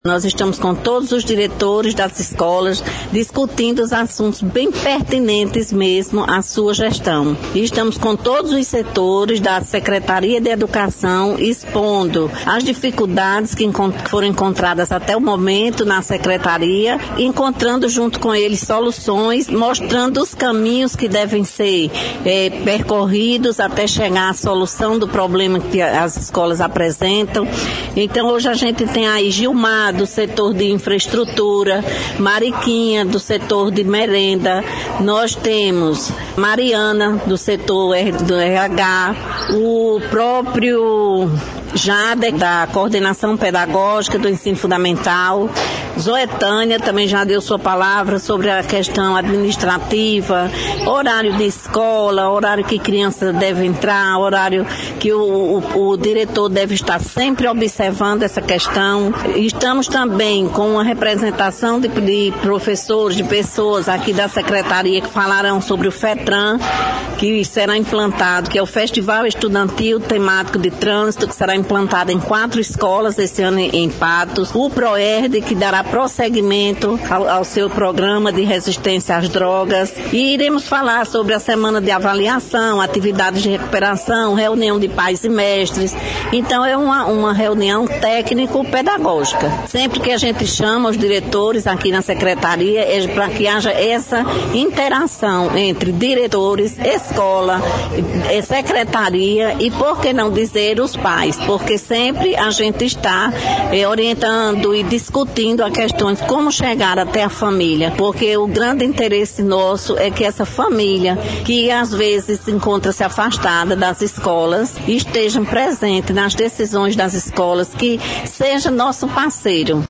O evento aconteceu na sede da própria Secretaria de Educação, durante os turnos manhã e tarde, com intervalo para o almoço.